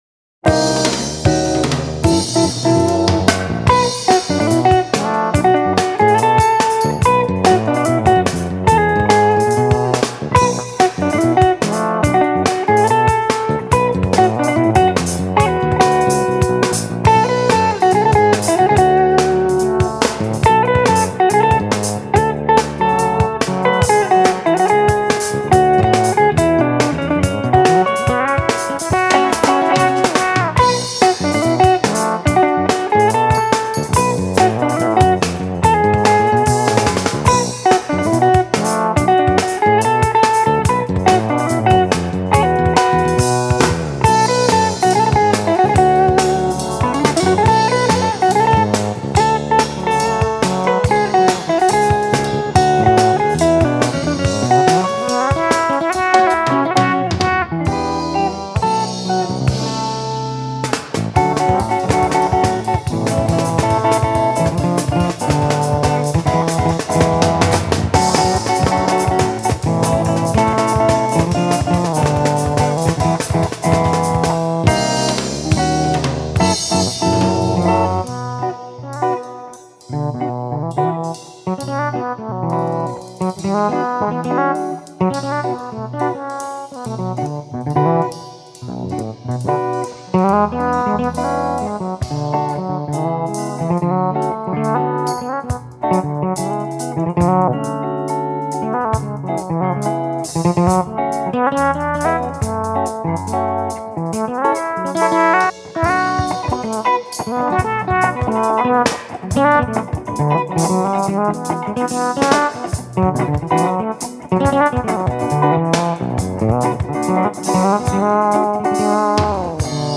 Instrumental Jazz
Música popular: instrumental y jazz